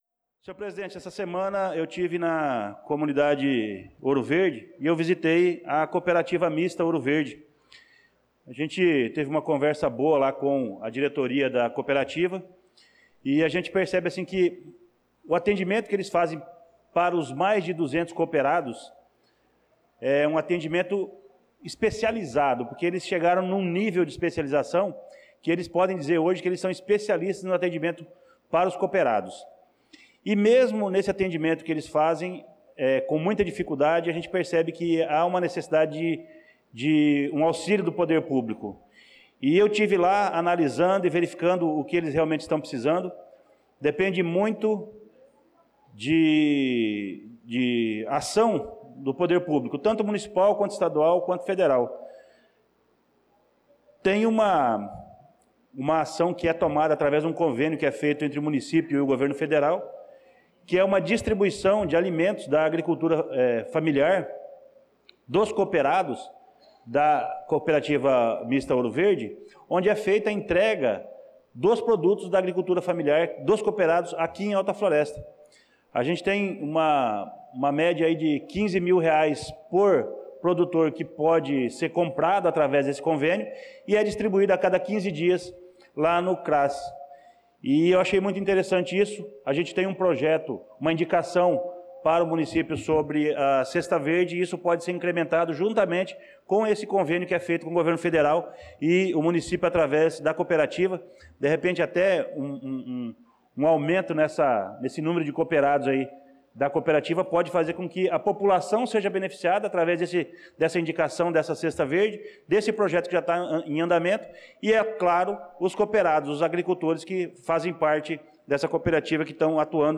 Pronunciamento do vereador Luciano Silva na Sessão Ordinária do dia 16/06/2025